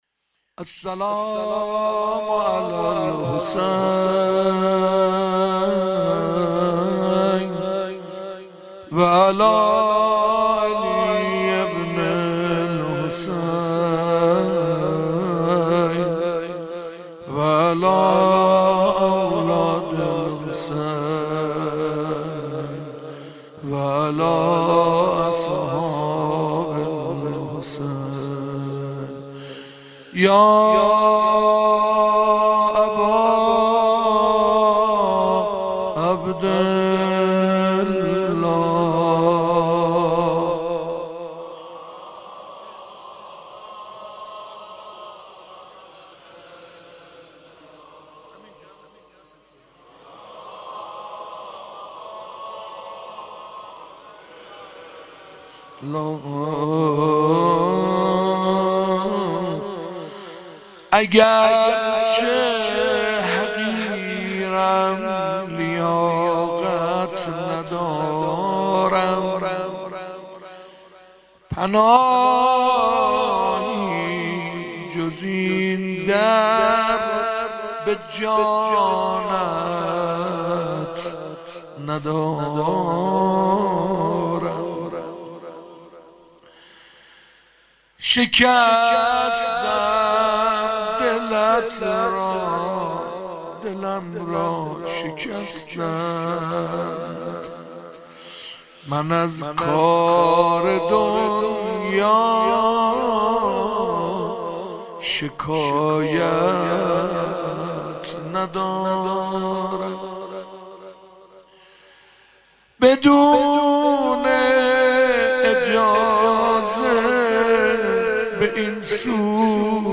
شام غریبان محرم 96
غزل